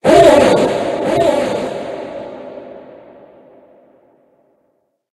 Cri de Pomdrapi Gigamax dans Pokémon HOME.
Cri_0841_Gigamax_HOME.ogg